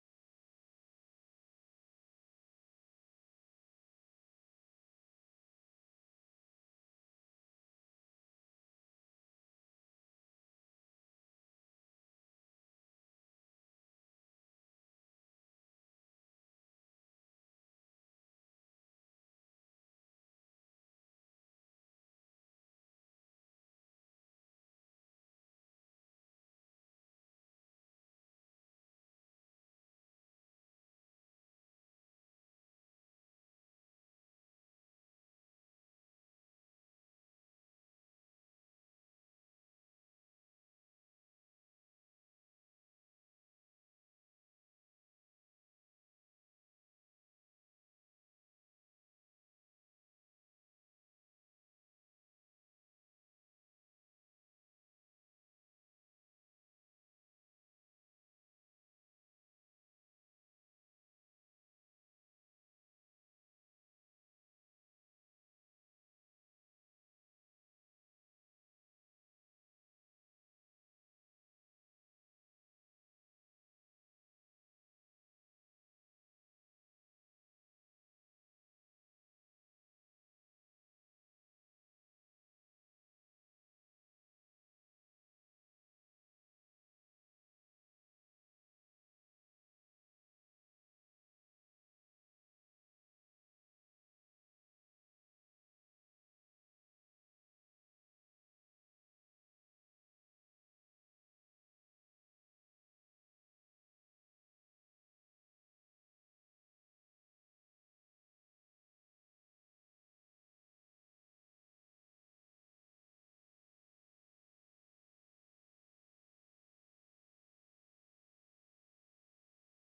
Konzeptueller Klingelton für Mobiltelefon, digitale Dateien Word Dokument, 4:33 Min., 2007 Courtesy of the artist and Modernism Gallery, San Francisco
Der Konzeptkünstler Jonathon Keats hat eine 4 Minuten und 33 Sekunden lange Zeitspanne absoluter Stille als mobilen Klingelton digital generiert.
My Cage (Silence for Cellphone) verzichtet auf Musiker, Klavier und Konzertsaal und benutzt stattdessen einen fortwährenden Stream computergenerierter und in Klingelton-Qualität komprimierter Stille.